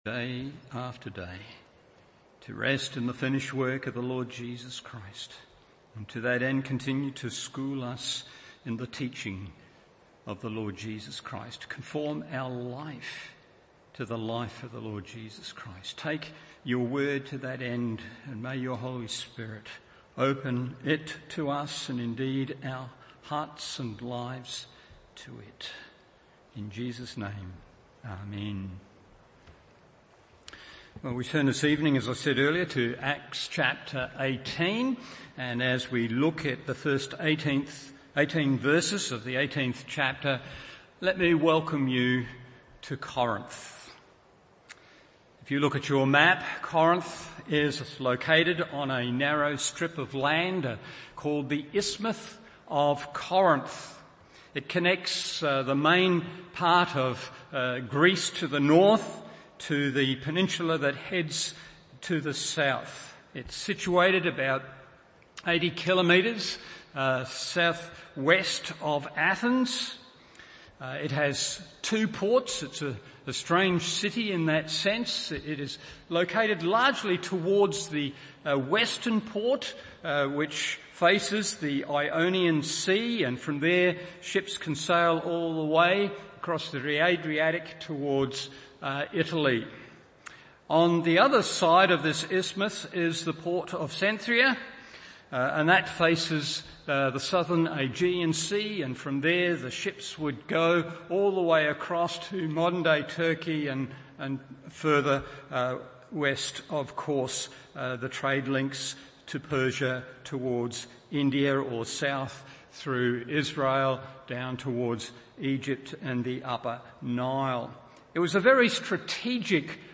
Evening Service Acts 18:1-18 1. The Challenge in Ministry 2. The Confidence in Ministry 3. The Comfort in Ministry…